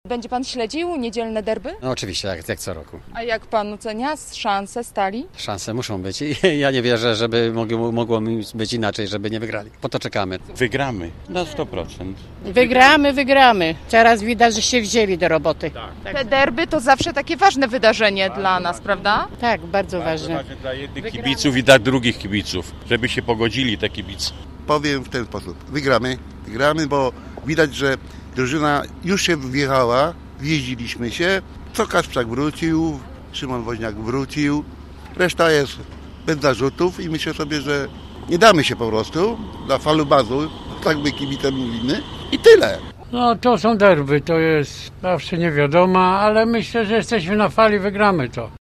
Gorzowscy kibice żużla są przekonani, że gorzowska Stal wyjdzie z derbowego spotkania zwycięsko: